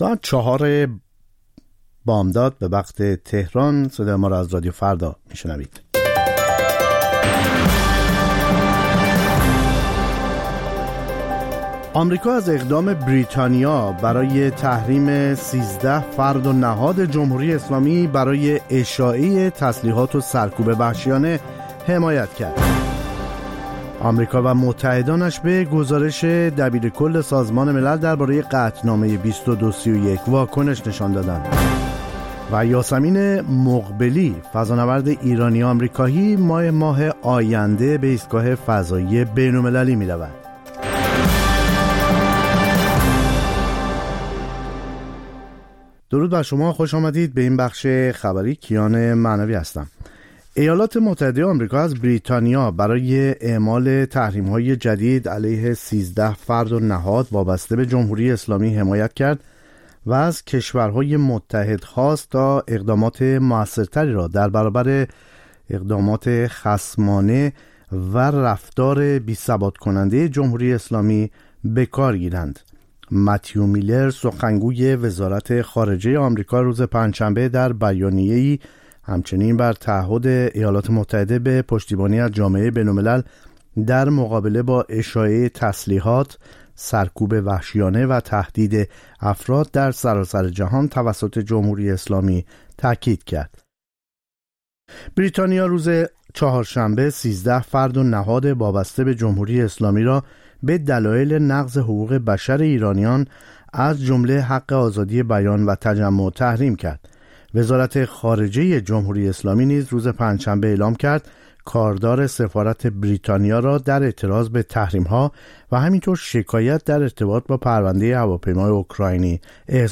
سرخط خبرها ۵:۰۰